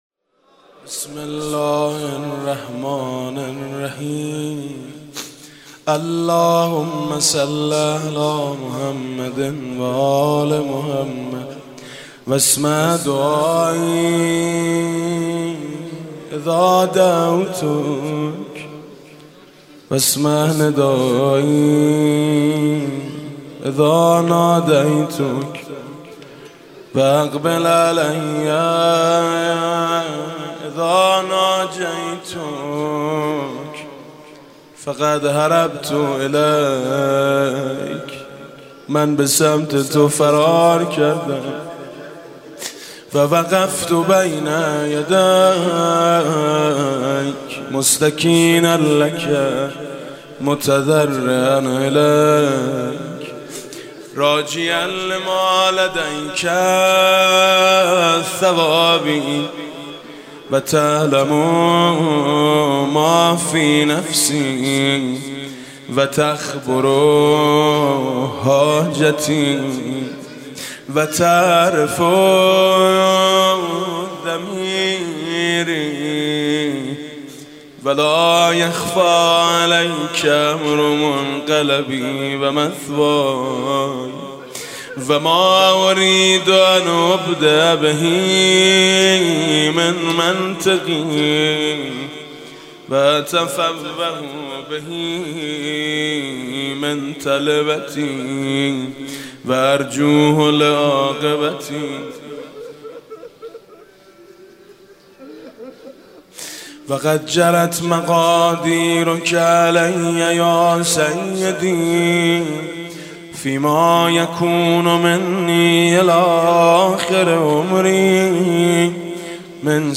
صوت قرائت مناجات شعبانیه با نوای میثم مطیعی مداح اهل‌بیت(ع) را می‌شنوید.